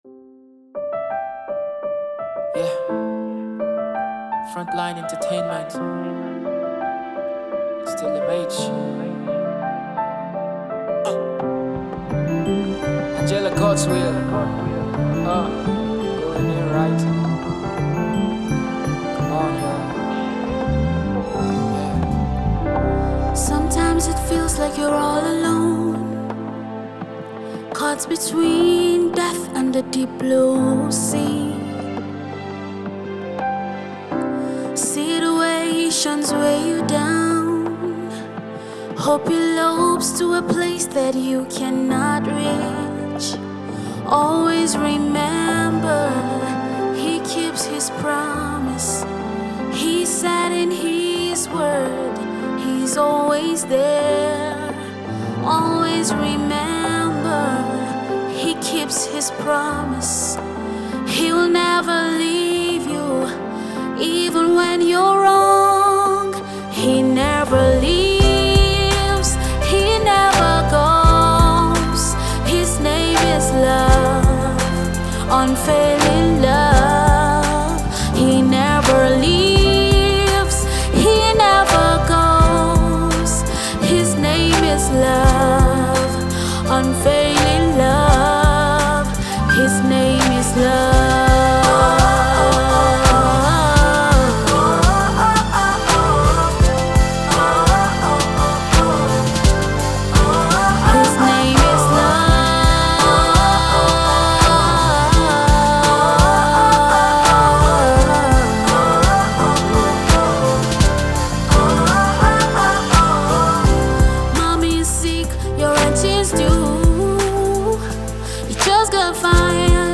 Amazing voices.